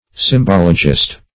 Search Result for " symbologist" : The Collaborative International Dictionary of English v.0.48: Symbologist \Sym*bol"o*gist\, n. One who practices, or who is versed in, symbology.